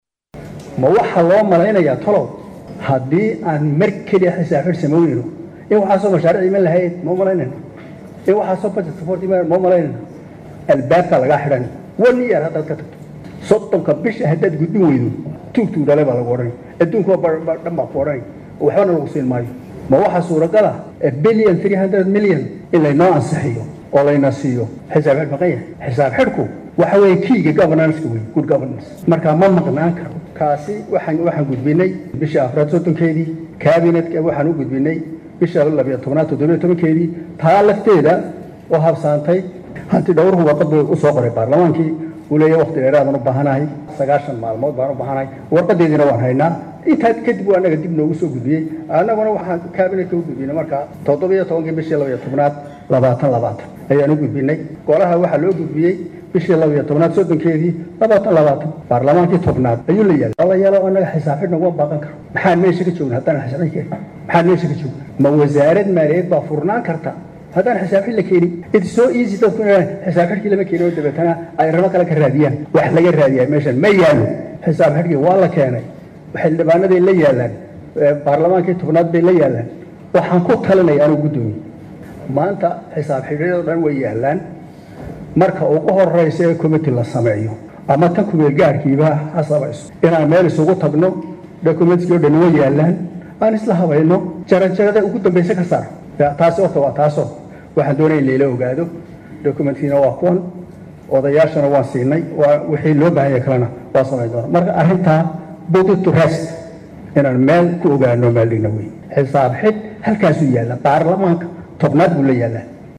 Wasiirka wasaaradda Maaliyadda Xukuumadda Xilgaarsiinta Soomaaliya Cabdiraxmaan Ducaale Beyle ayaa hor tagay Baarlamaanka Golaha Shacabka, iyadoo Su’aalo laga weydiiyay arrimaha ku aaddan Miisaaniyadda Dowladda.
Wasiirka oo arrimahan ka hadlay ayaa waxa hadalladiisa ka mid ah.